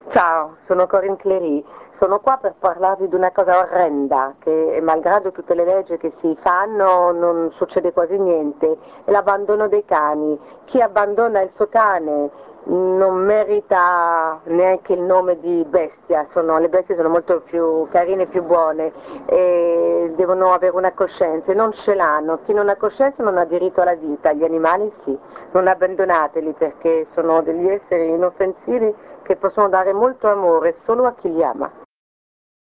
ASCOLTA GLI SPOT DI CORINNE CLERY
Spot 2 (Contro l'abbandono)